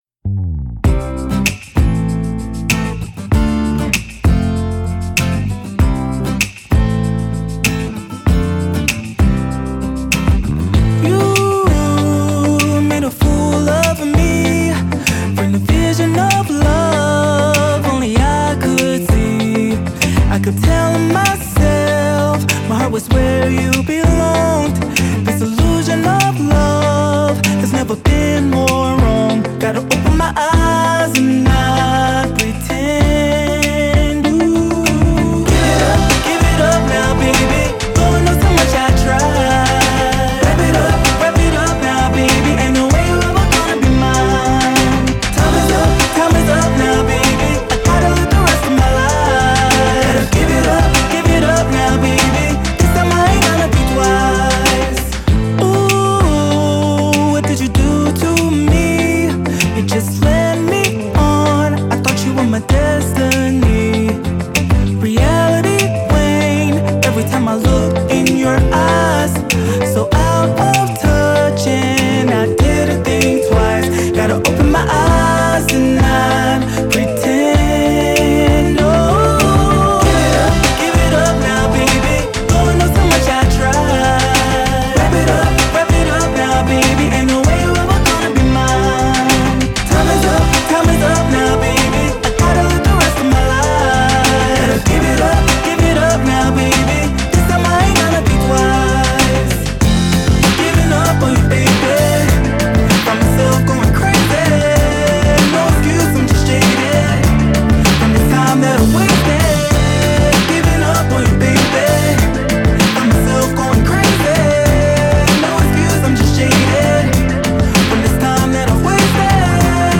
Each of these songs blend various genres together